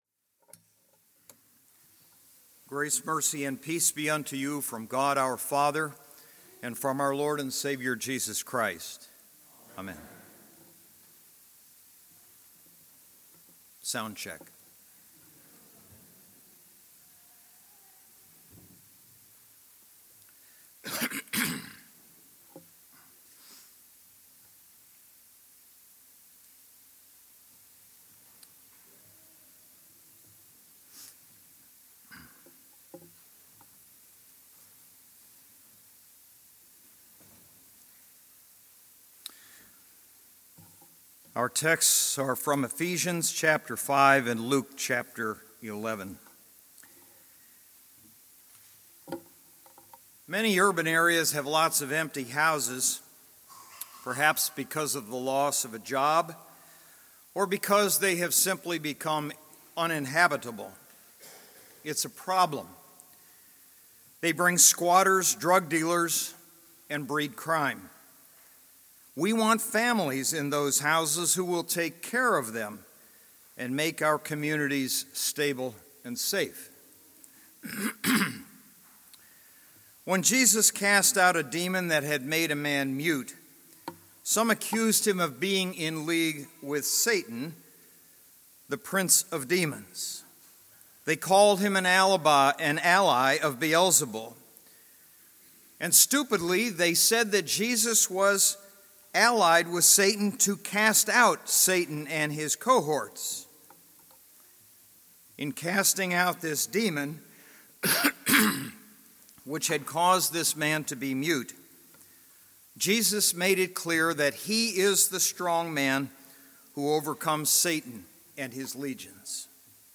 Third Sunday in Lent – Oculi